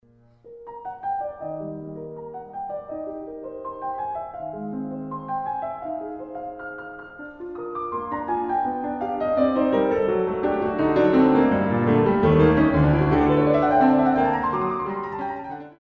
De doorwerking begint met een bekende formule: het begin van het eerste thema komt terug maar nu opeens in mineur. En dan gaat hij op een canonische manier spelen met de kop van dat thema, totdat plotseling fortissimo het thema in de bas komt met een snelle triolenbeweging in de rechterhand.
Die passage eindigt met een zogenaamd orgelpunt op de dominant.